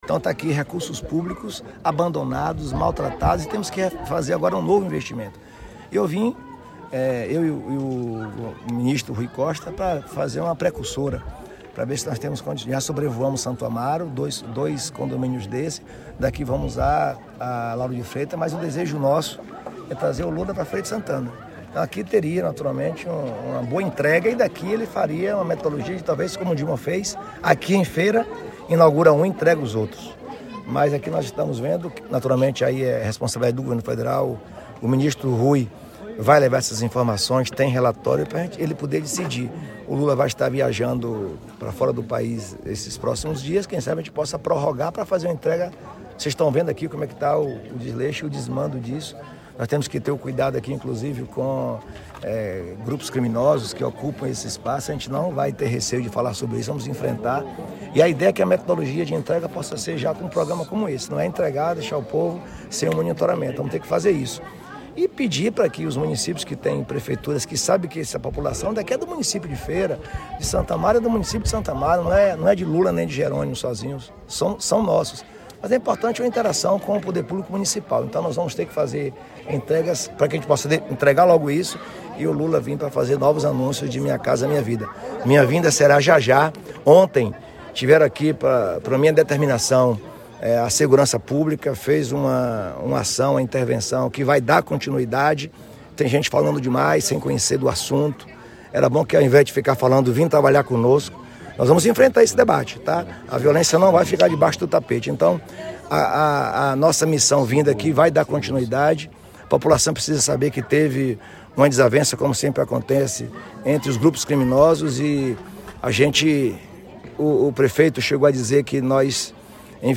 Sonoras Jerônimo e Rui